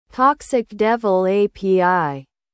Google Text-To-Speech Converts text to speech.
google_tts